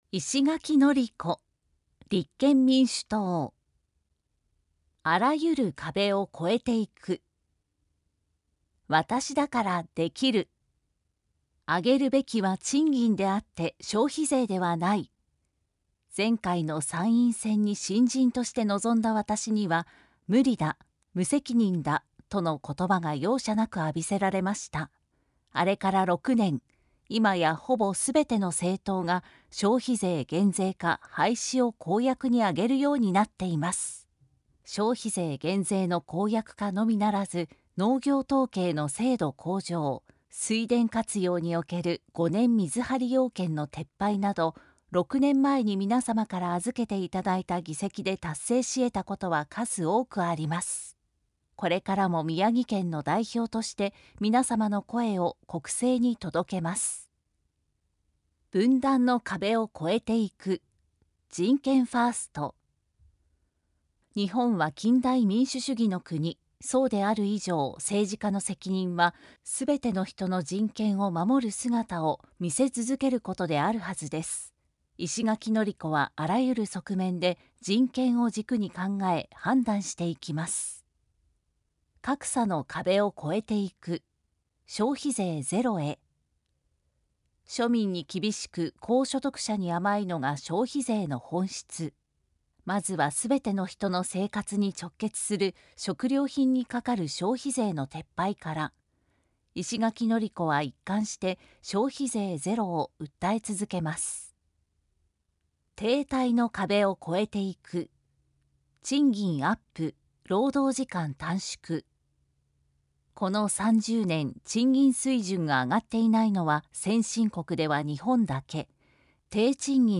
参議院議員通常選挙候補者・名簿届出政党等情報（選挙公報）（音声読み上げ用）（音声版）
選挙公報音声版（MP3：4,110KB）